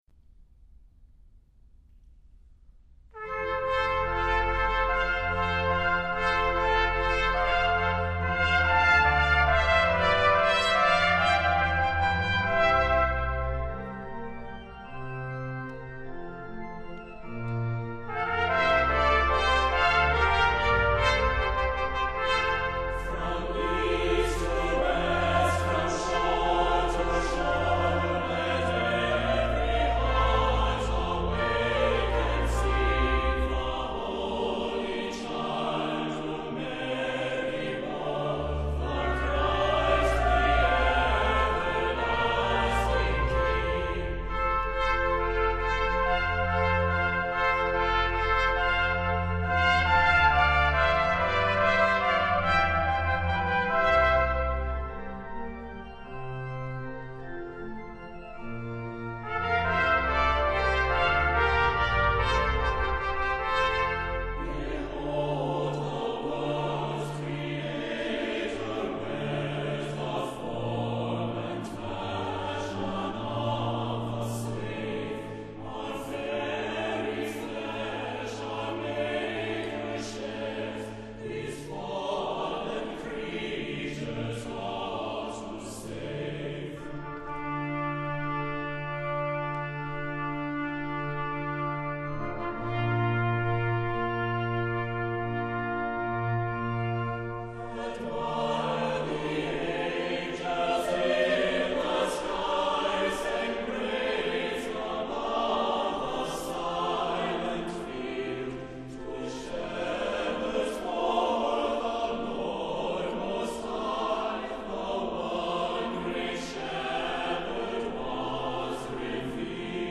Choral and organ–
A small Full Score (organ and trumpets)–(0426sm)